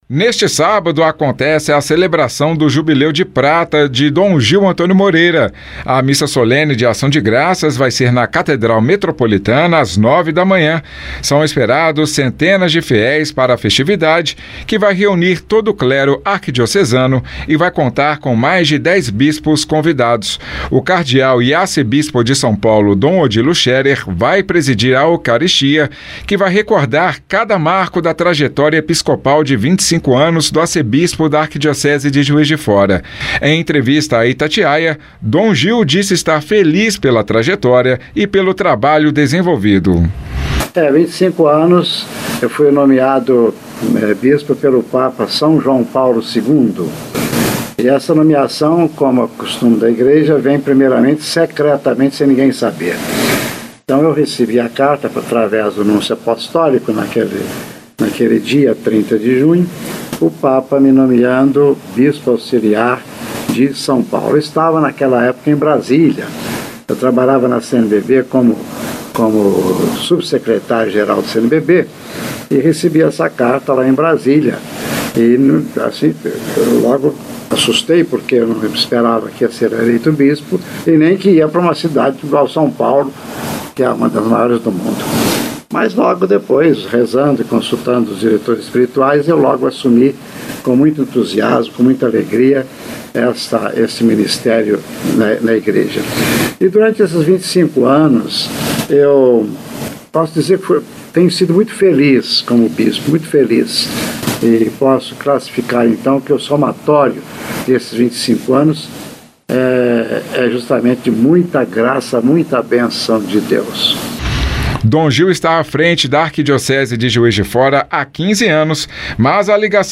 Em entrevista à Itatiaia, Dom Gil Moreira disse estar feliz pela trajetória e pelo trabalho desenvolvido. Ele também compartilhou que era criança quando ouviu falar pela primeira vez de Juiz de Fora.